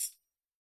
标签： 多次采样 塔姆伯林 样品 铃鼓 编辑
声道立体声